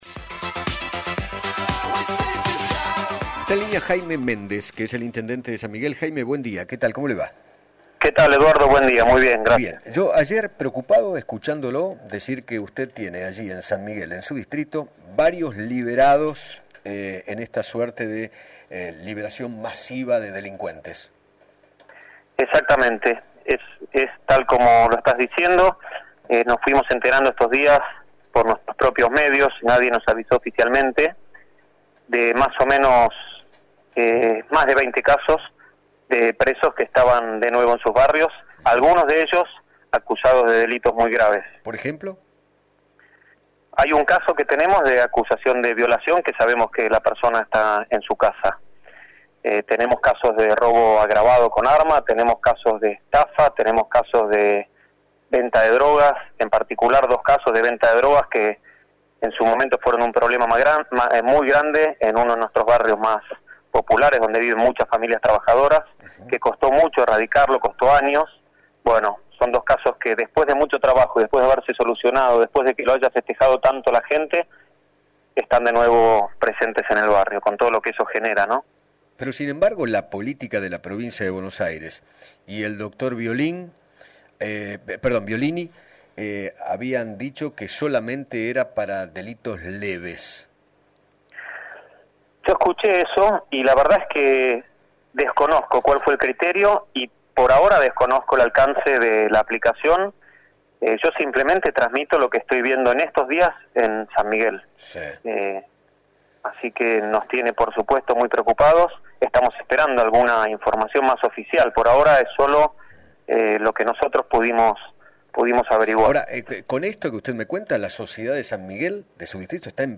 Jaime Méndez, Intendente de San Miguel, dialogó con Eduardo Feinmann sobre su preocupación por la excarcelación de más de veinte presos acusados de delitos muy graves que volvieron a esa localidad.